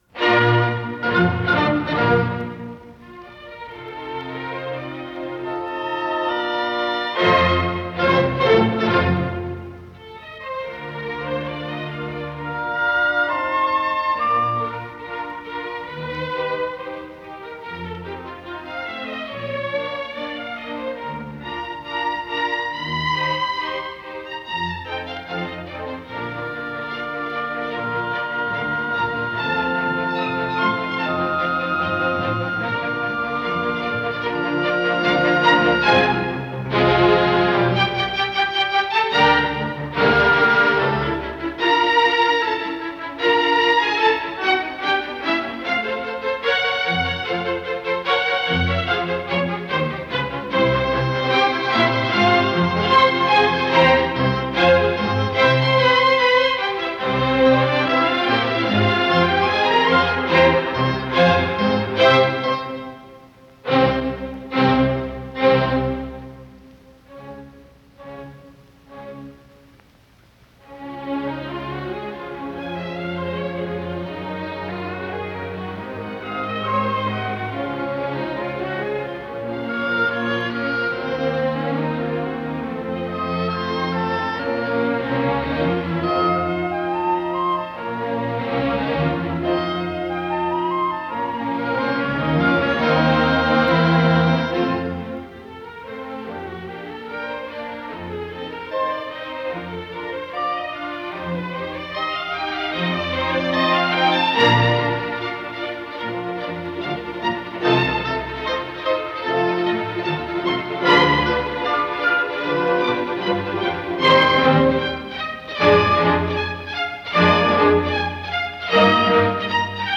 Исполнитель: Уильям Каппель - фортепиано
для фортепиано с оркестром
си бемоль мажор